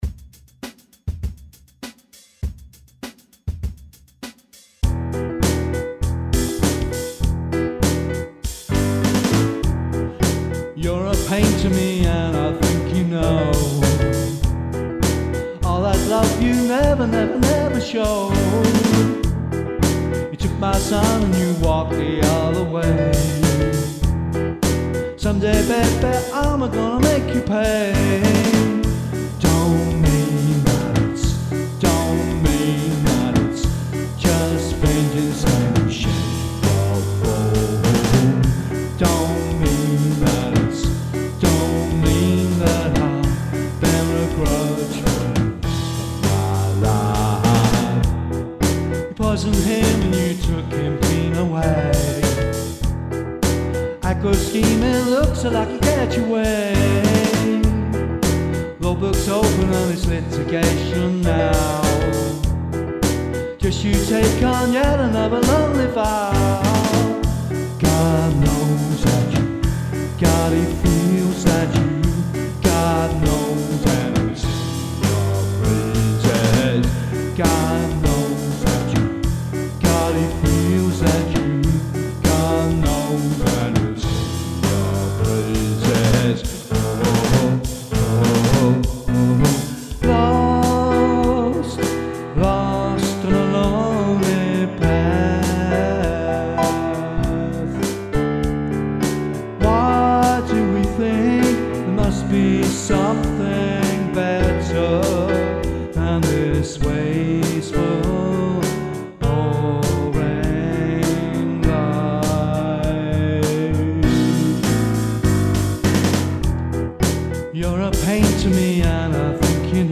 4:14/100bpm